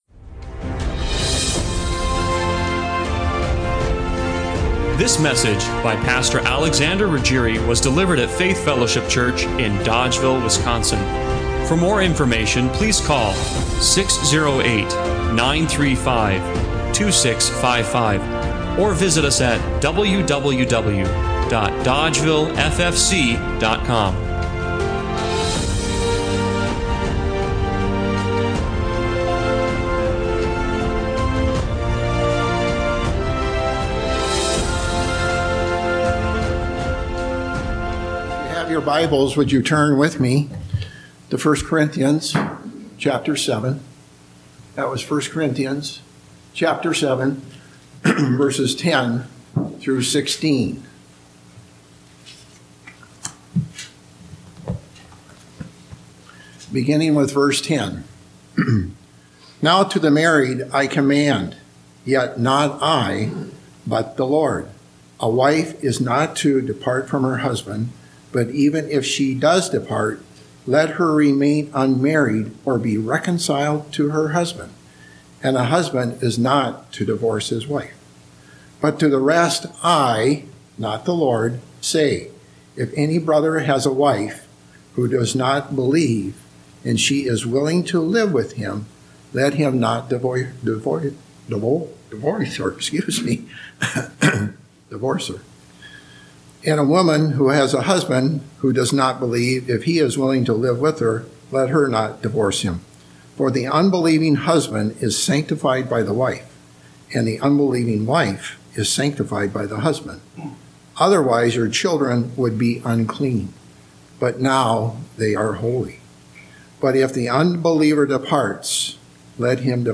1 Corinthians 7:10-16 Service Type: Sunday Morning Worship The brokenness caused by divorce is everywhere.